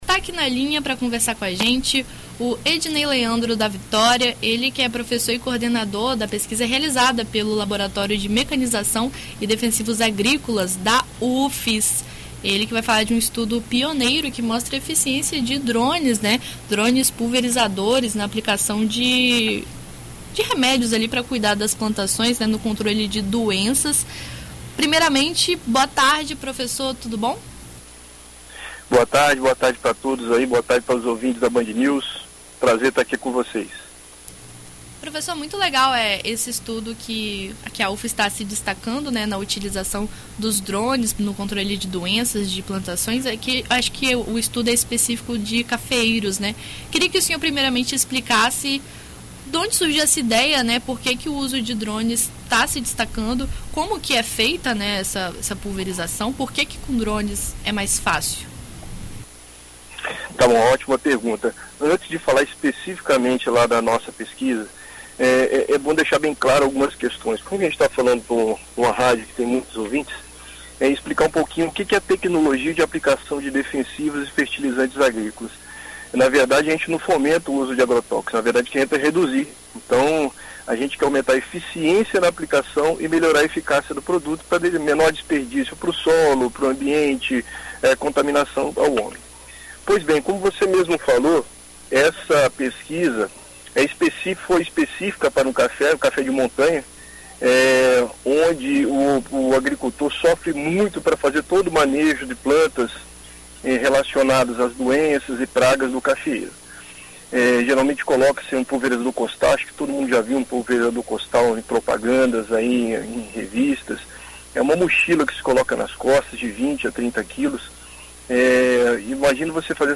Em entrevista a Rádio BandNews FM ES